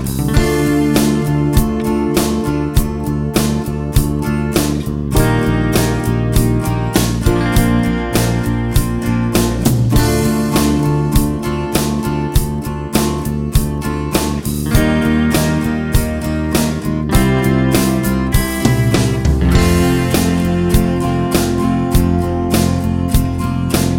No Main Electric Guitar Rock 6:04 Buy £1.50